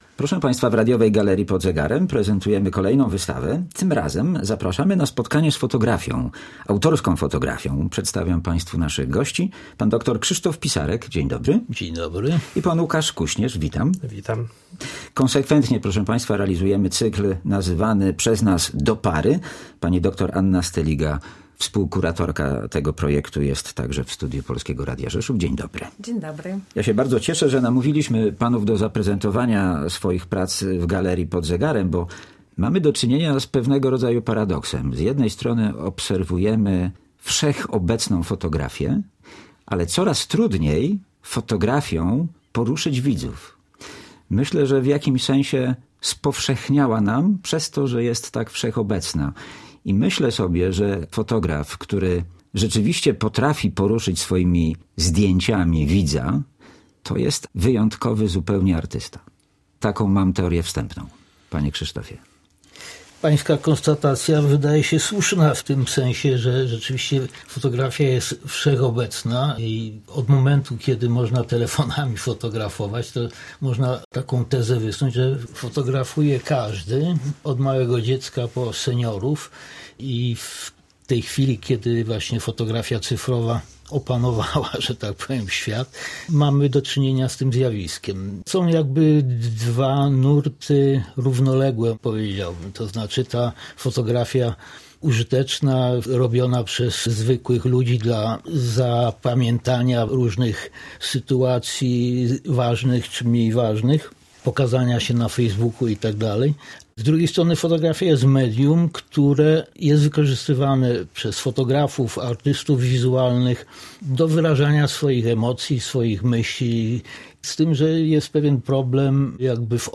Z artystami rozmawiają